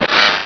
Cri de Carvanha dans Pokémon Rubis et Saphir.